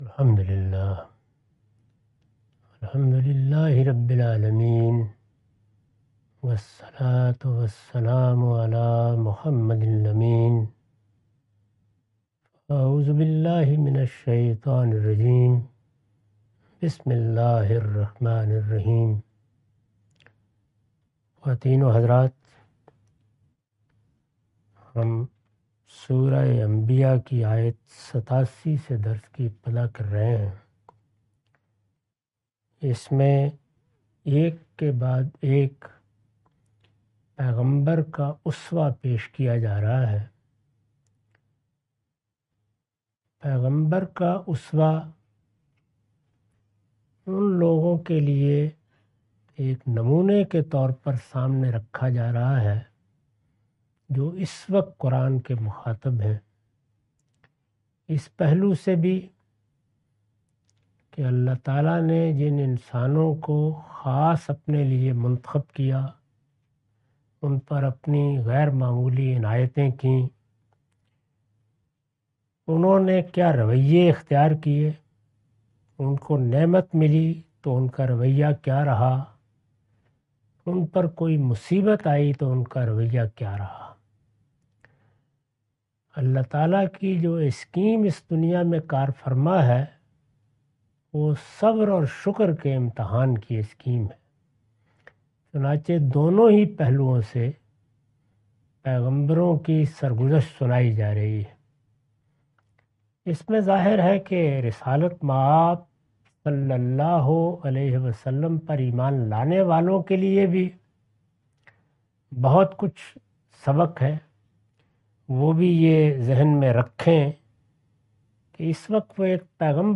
Surah Al-Anbiya A lecture of Tafseer-ul-Quran – Al-Bayan by Javed Ahmad Ghamidi. Commentary and explanation of verse 87.